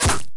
strike2.wav